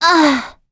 Gremory-Vox_Damage_05.wav